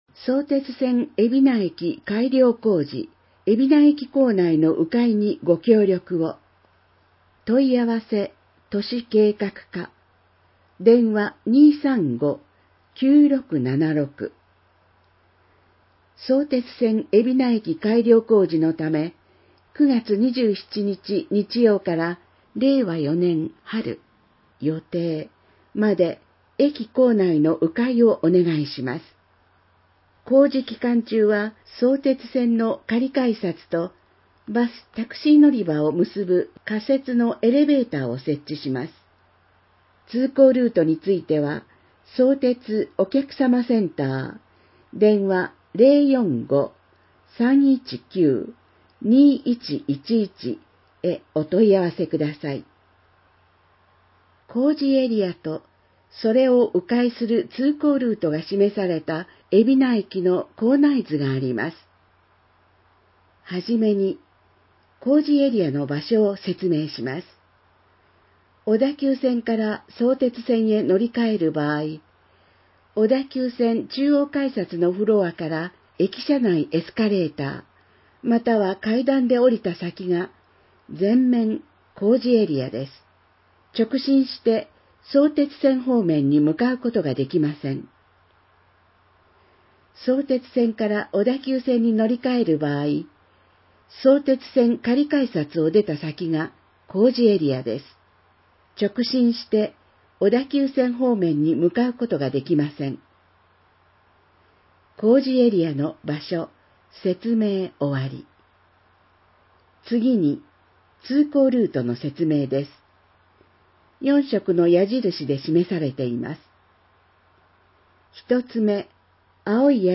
広報えびな 令和2年9月15日号（電子ブック） （外部リンク） PDF・音声版 ※音声版は、音声訳ボランティア「矢ぐるまの会」の協力により、同会が視覚障がい者の方のために作成したものを登載しています。